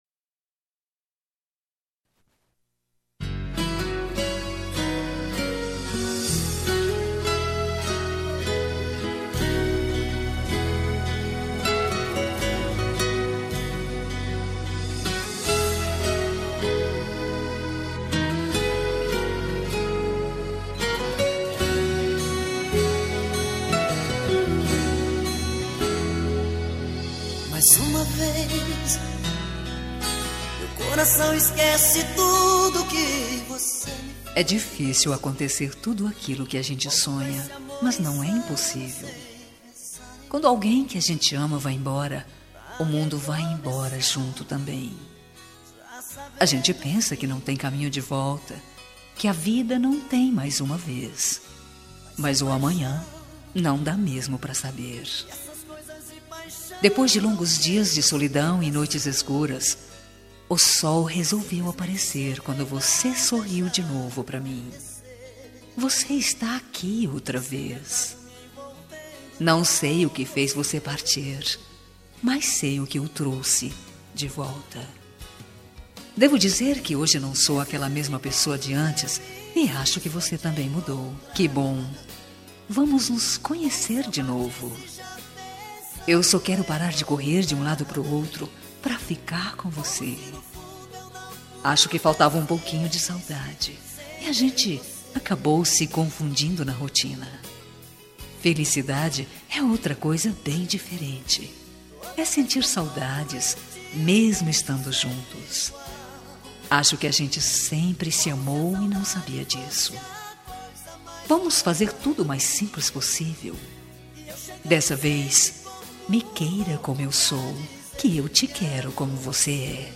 Telemensagem de Agradecimento – Por Voltar – Voz Feminina – Cód: 08